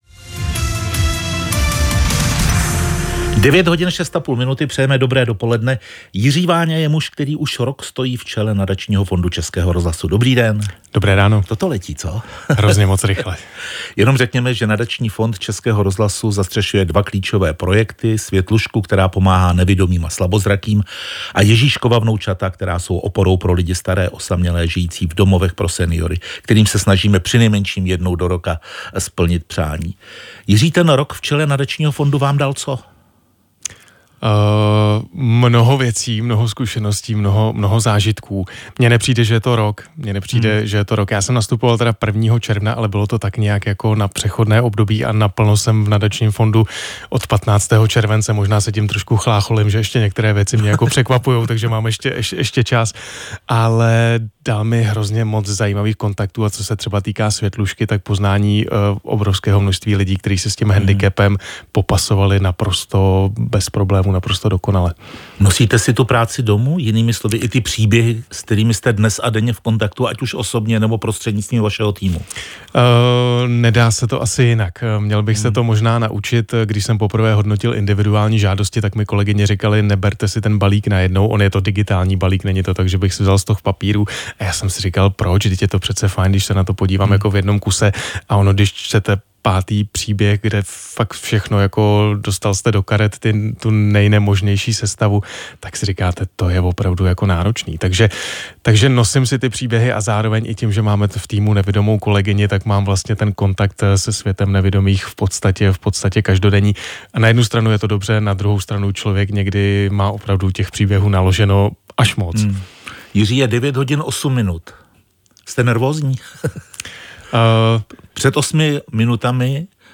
Hlavní zprávy - rozhovory a komentáře: Odpolední publicistika: Hlasování o nedůvěře vládě. Izraelsko-íránský konflikt. Falešní zubaři - 18.06.2025